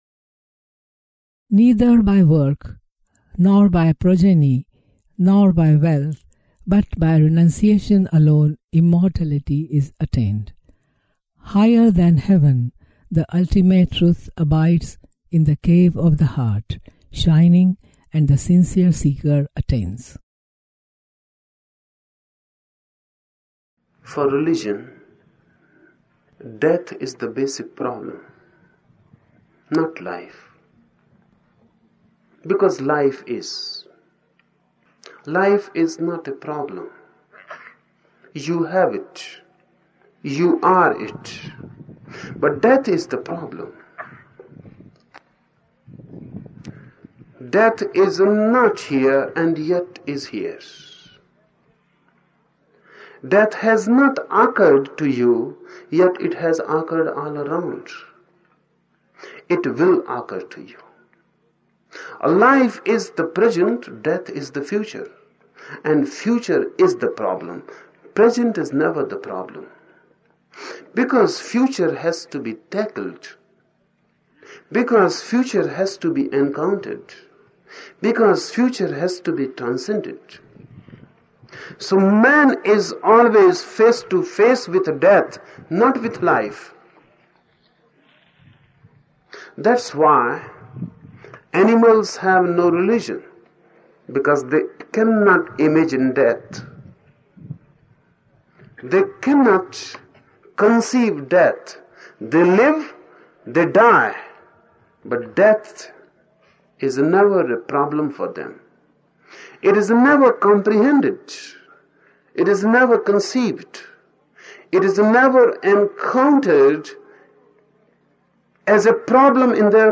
Each program has two parts, Listening Meditation (Osho discourse) and Satsang Meditation.
The Osho discourses in the listening meditations in this module are mostly from talks in which Osho was speaking on the Upanishads and were given at various meditation camps held in India between 1972 and 1974.